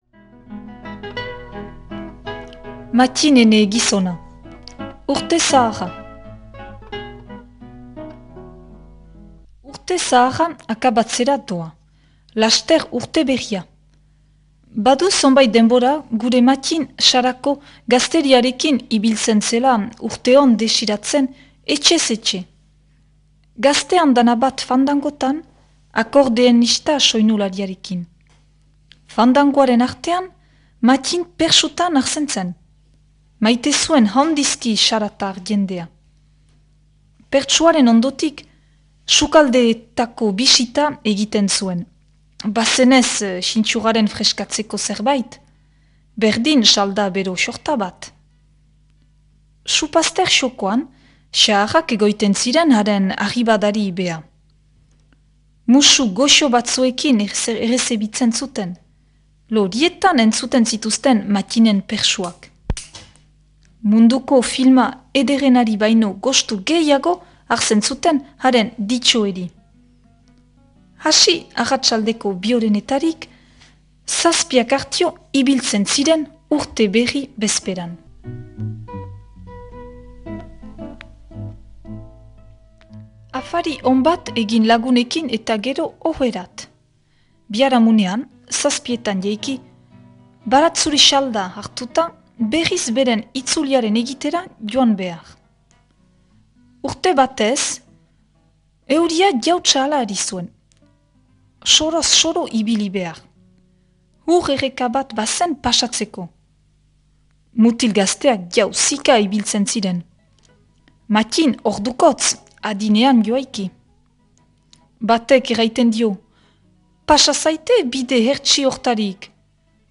irakurketa